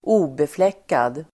Uttal: [²'o:beflek:ad]